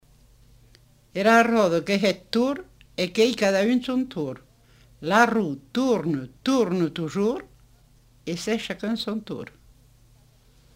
Lieu : Montauban-de-Luchon
Effectif : 1
Type de voix : voix de femme
Production du son : récité
Classification : proverbe-dicton